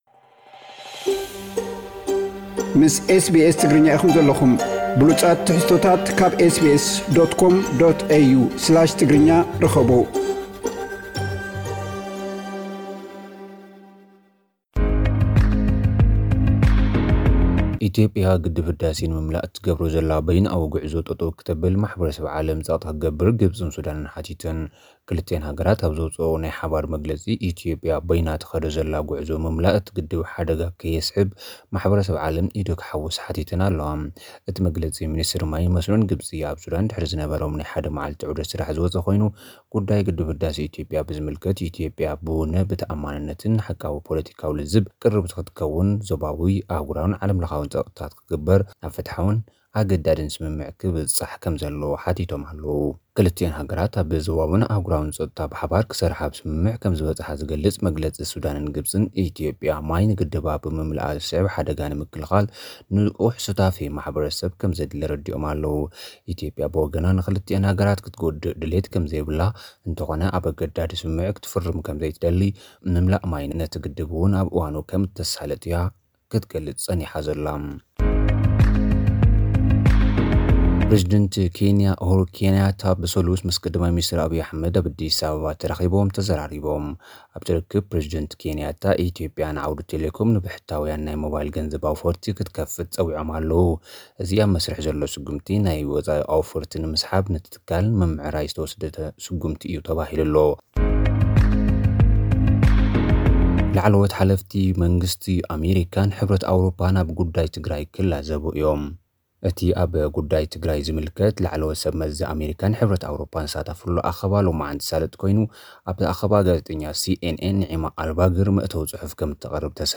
ግብጽን ሱዳንን ማሕበረሰብ ዓለም ኢትዮጵያ ኣብ ሩባ ኣባይ ትገብሮ ዘላ በይነኣዊ ጉዕዞ ምምላእ ማይ ደው ከብለለን ሓቲተን። (ሓጸርቲ ጸብጻብ)